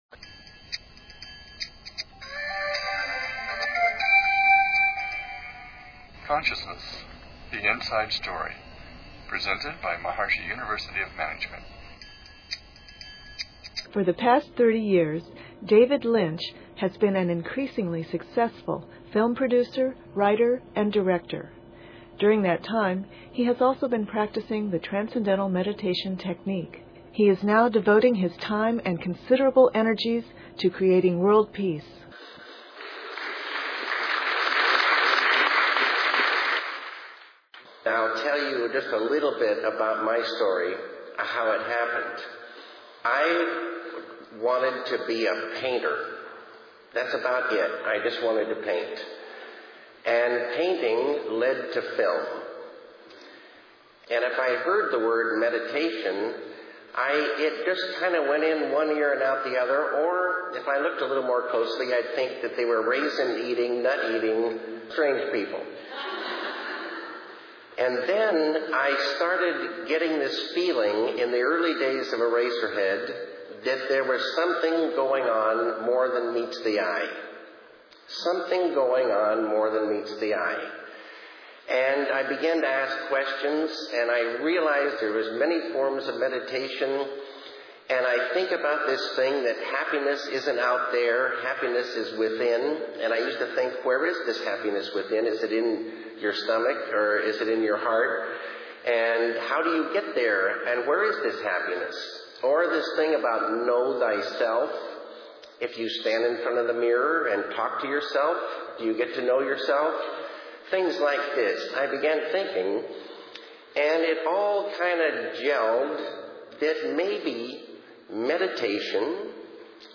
We also have some new audio interviews with Lynch this week from the Maharishi's University .
And lastly a clip from Lynch's talk at American University on TM and Peace .